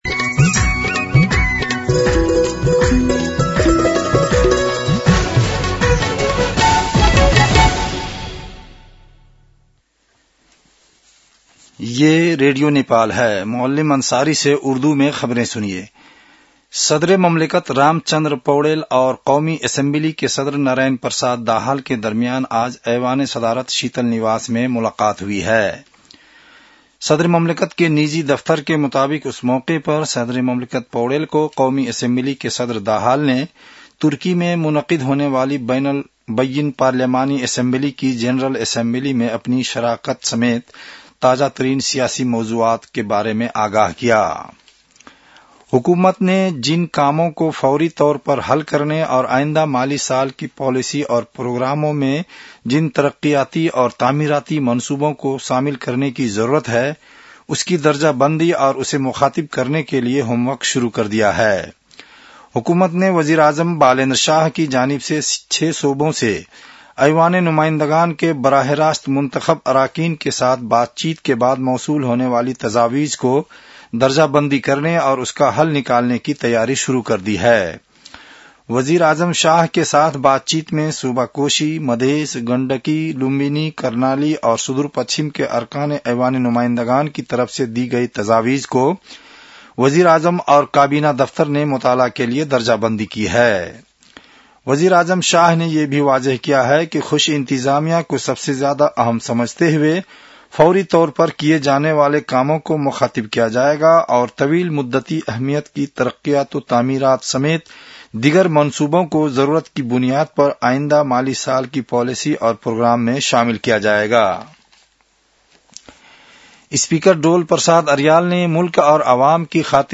An online outlet of Nepal's national radio broadcaster
उर्दु भाषामा समाचार : २९ चैत , २०८२